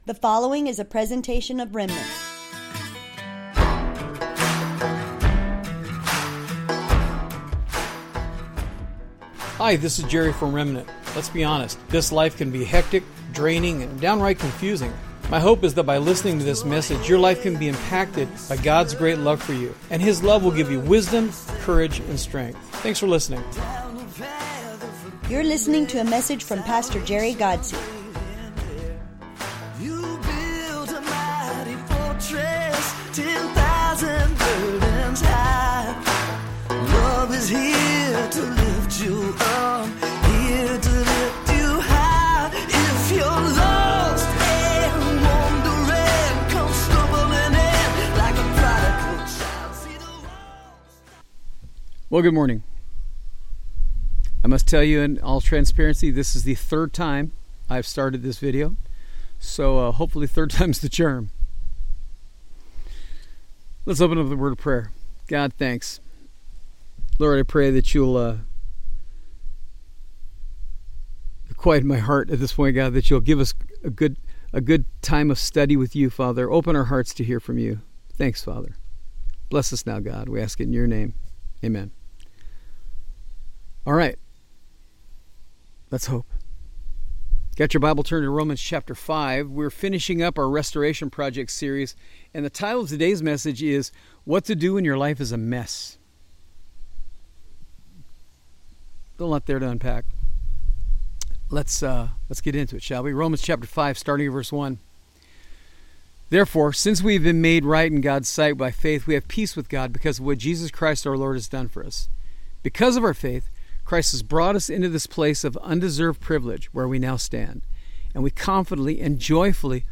A Bible study from Remnant Church in El Centro